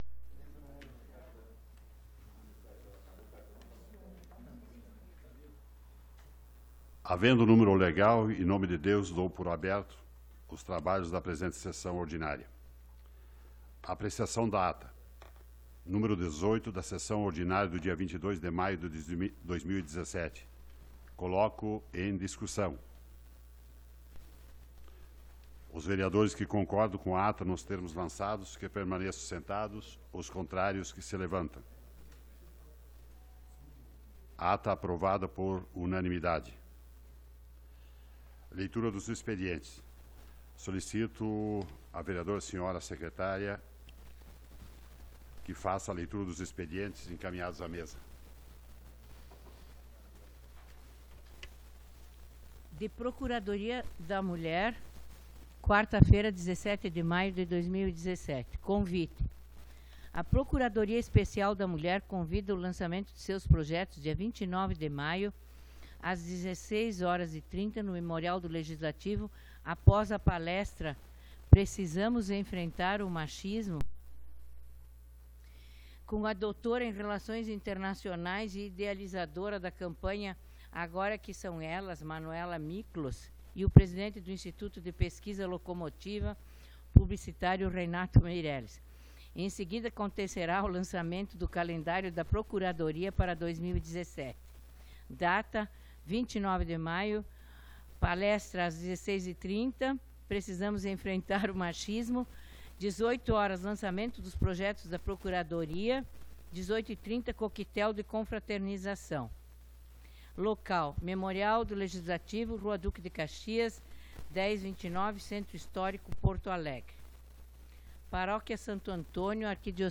Sessão Ordinária do dia 22 de Maio de 2017